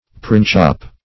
Printshop \Print"shop`\, n. A shop where prints are sold.